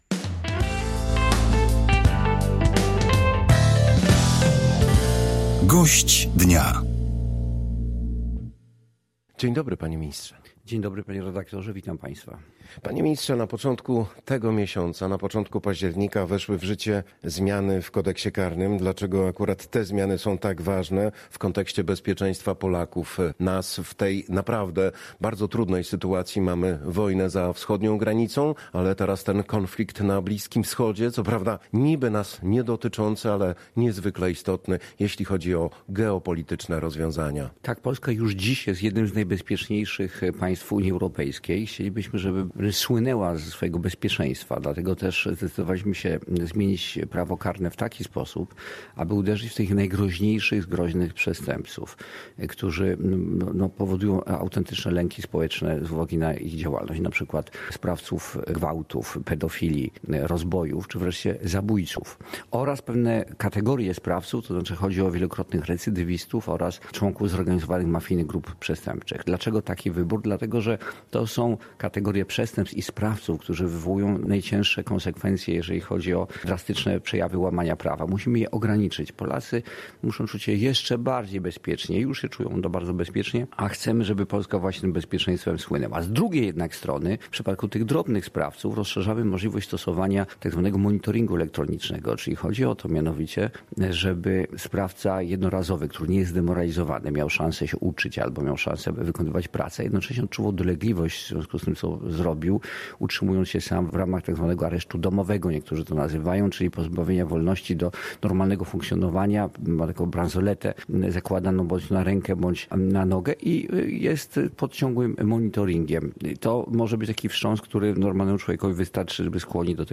Gość Polskiego Radia Rzeszów, minister sprawiedliwości Zbigniew Ziobro przypomniał, że 15 października wybierzemy nie tylko nowy parlament, ale także możemy mieć wpływ na kierunek polityki prowadzonej przez rząd.